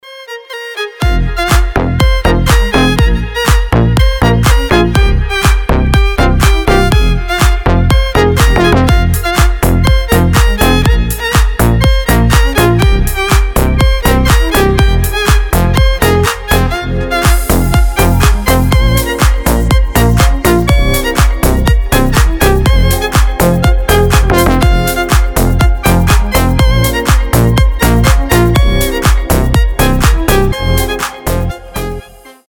• Качество: 320, Stereo
deep house
мелодичные
зажигательные
dance
без слов
Зажигательная музыка со скрипкой